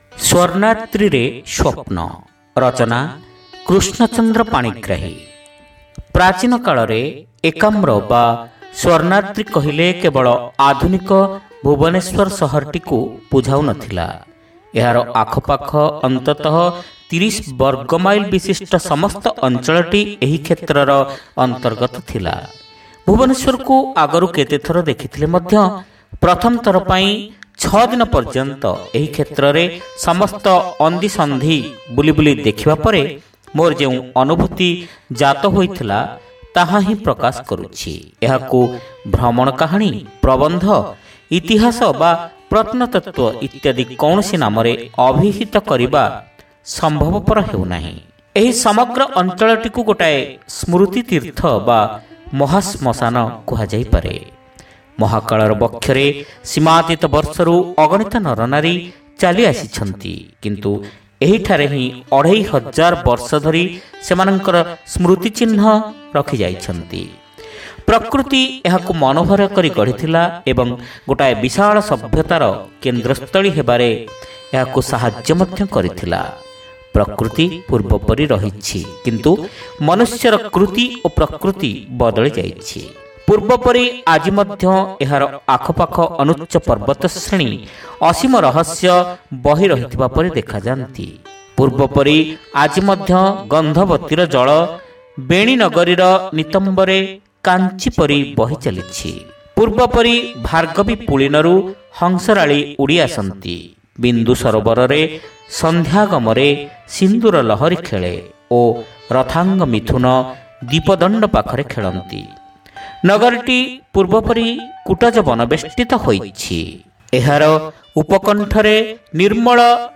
Audio Story : Swarnadri re Swapna